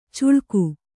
♪ cuḷku